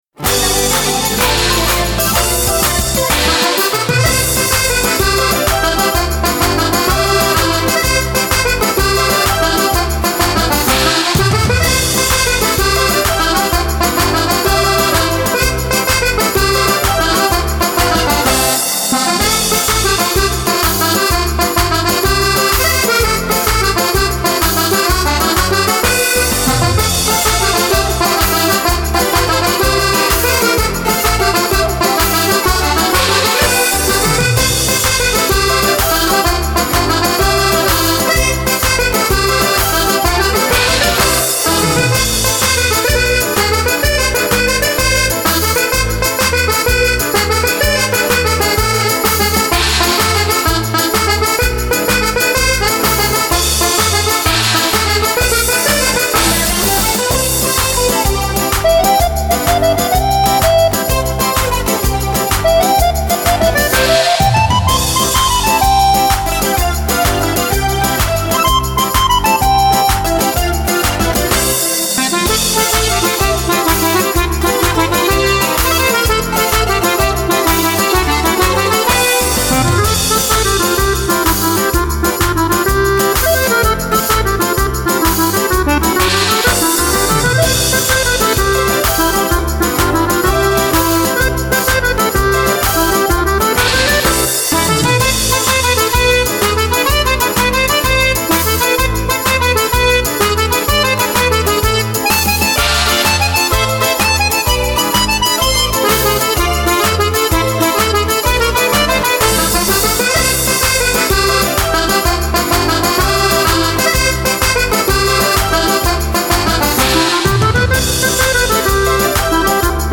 Version accordéon intégrale